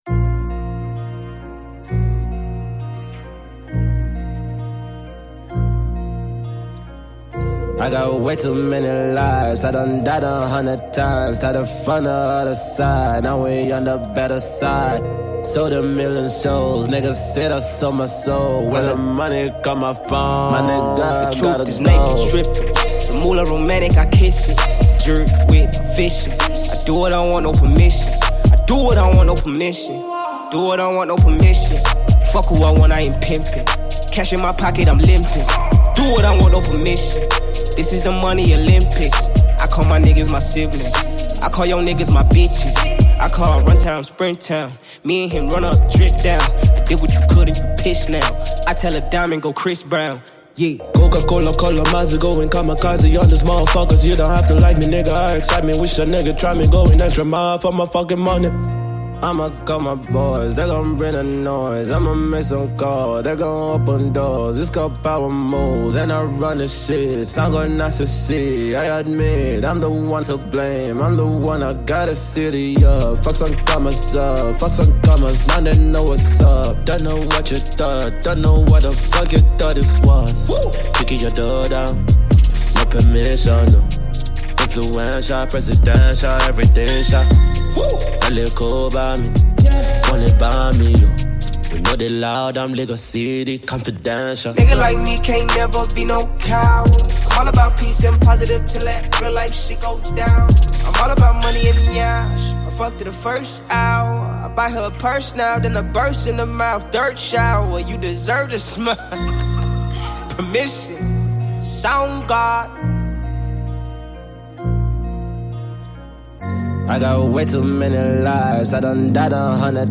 links up once again with South African rapper